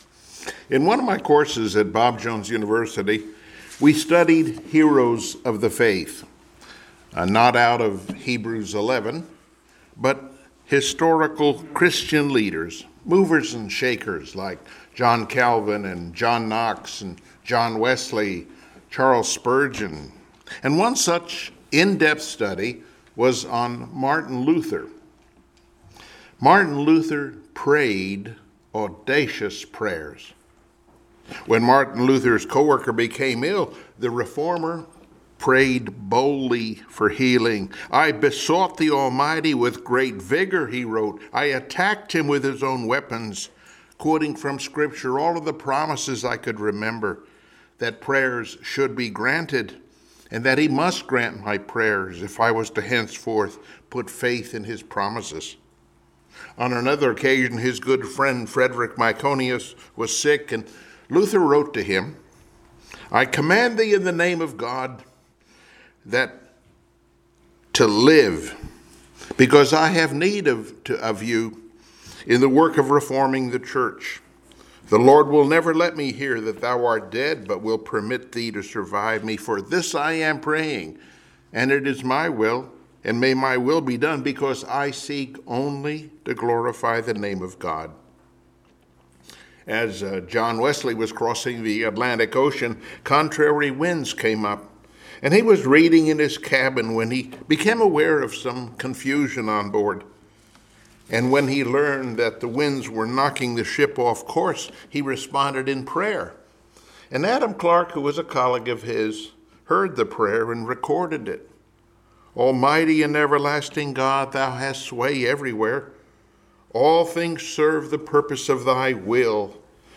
Joshua Passage: Joshua 9-10 Service Type: Sunday Morning Worship Topics